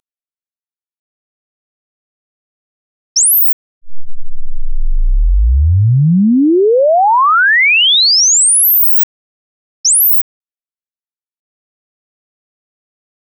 4秒、11秒各有一條斜線，那是REW用來識別對齊用的。中間那段像是指數上升的曲線，則是掃頻訊號。